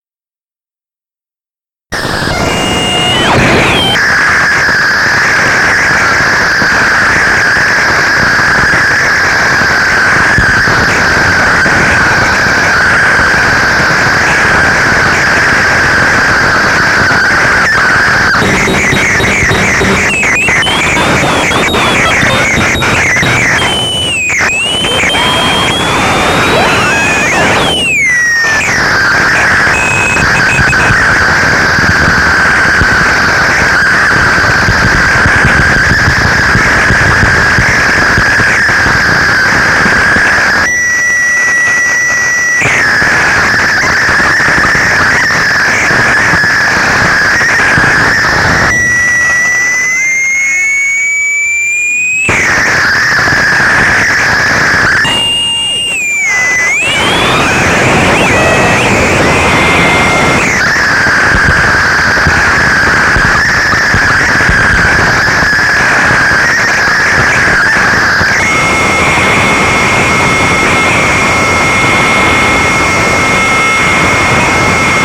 Digitally transferred in 2024 from the original tapes.
Other instruments used were guitar,
rhythm box and many other noise generators.